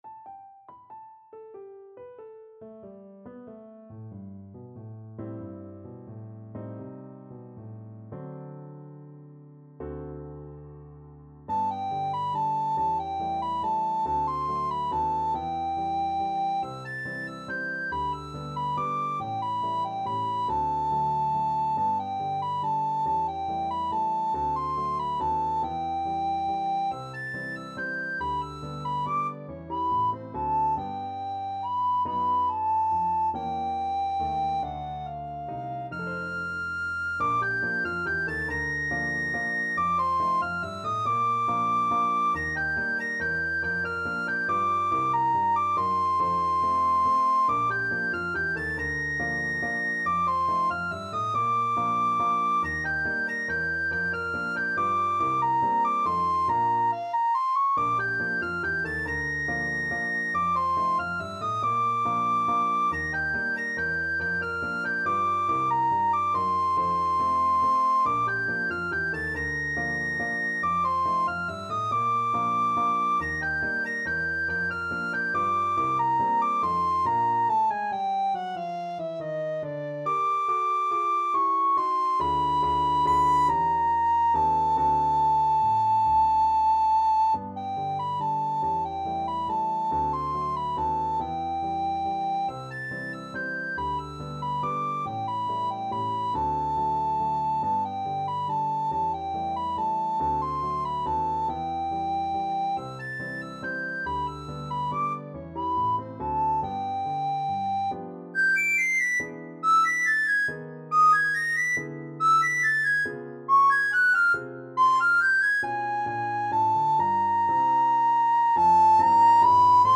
Free Sheet music for Soprano (Descant) Recorder
Valse Tempo a tempo. = 140
3/4 (View more 3/4 Music)
Jazz (View more Jazz Recorder Music)